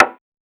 BWB WAVE 5 PERC (36).wav